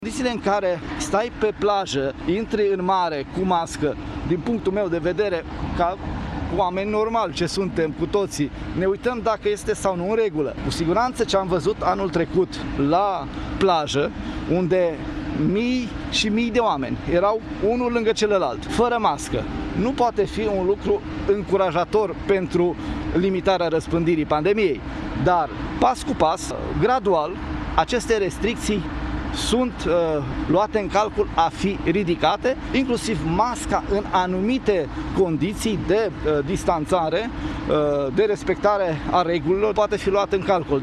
Ministrul Internelor a declarat, luni, că purtarea măștii de protecție sanitară este obligatorie și la munte, și la mare, dar se analizează renunțarea graduală la această măsură.